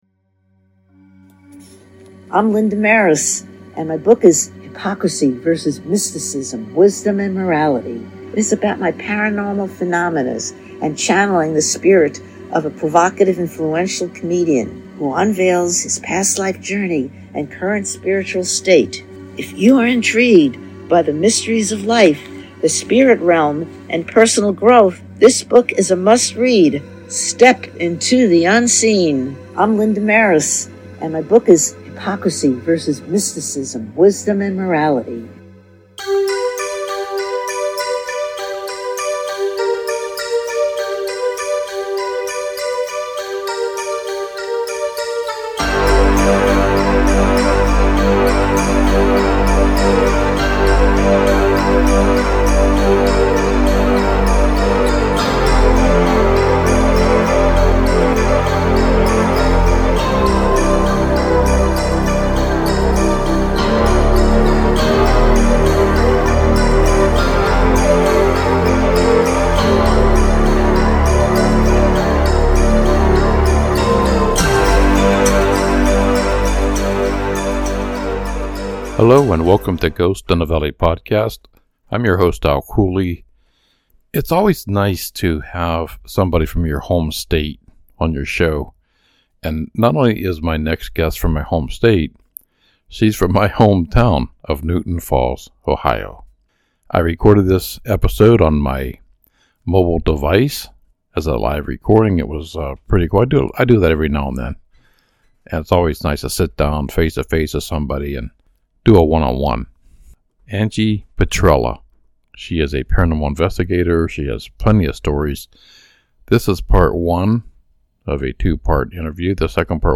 It's always cool to interview someone from your home state.